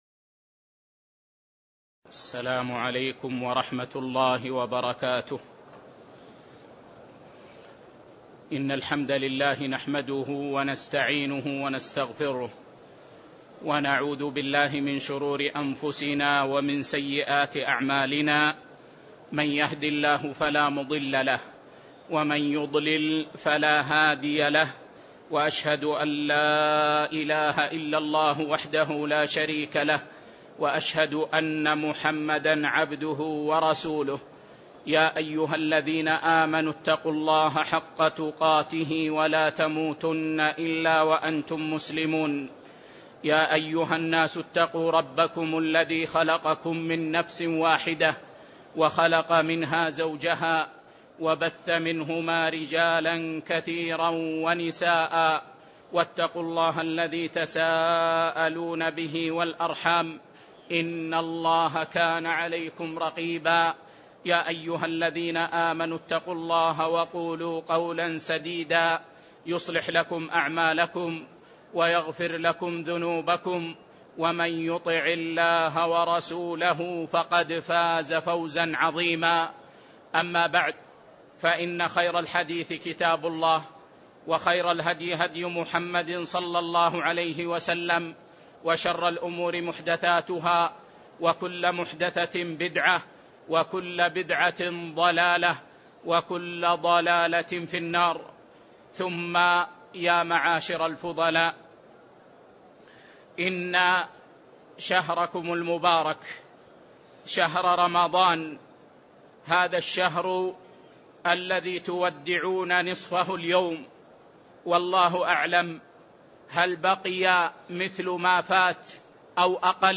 من دروس الشيخ في المسجد النبوي